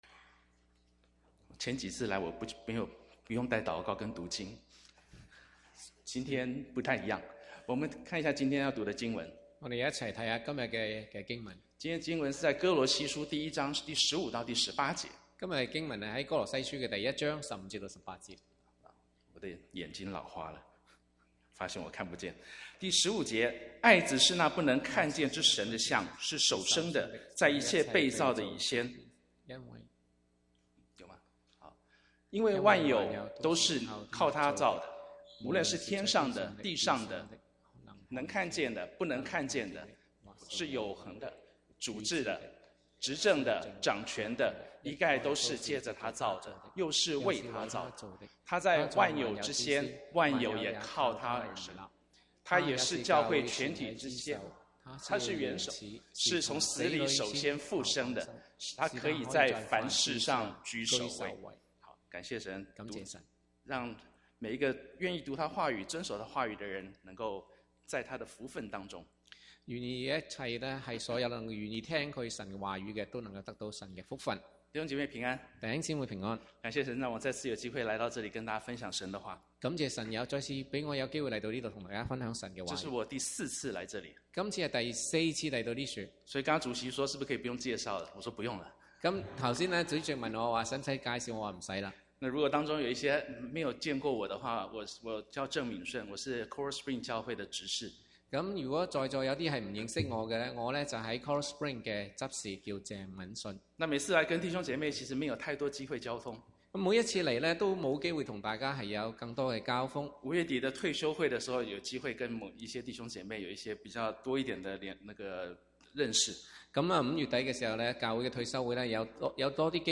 Chinese Sermons